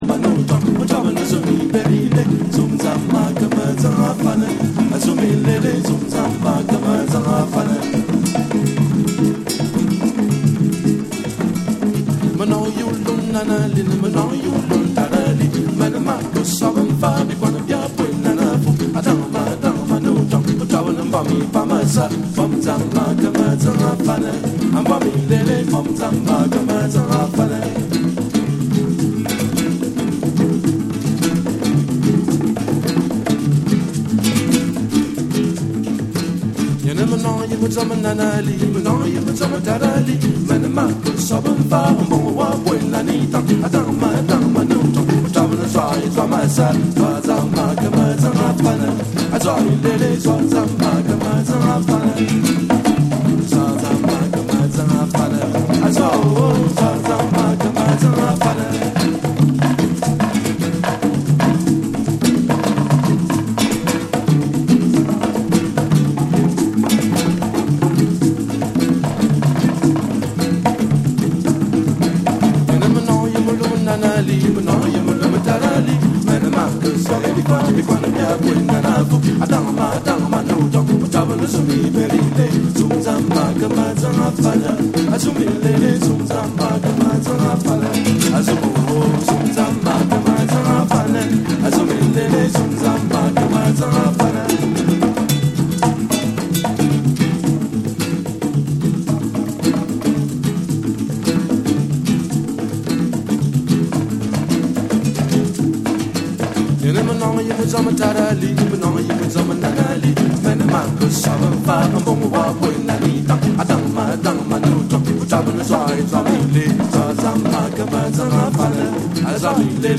カメルーン出身の3兄弟によって結成されたパーカッション・トリオによる、圧倒的なグルーヴとリズムの魔術が詰まったアルバム。